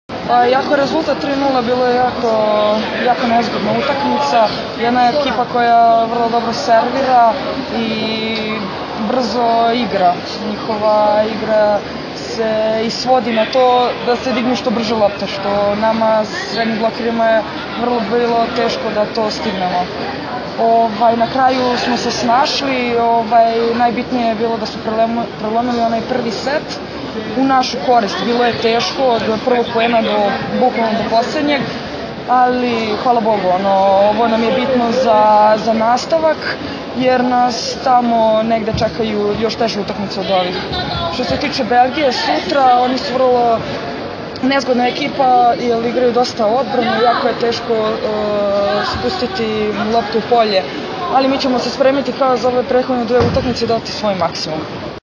IZJAVA JOVANE STEVANOVIĆ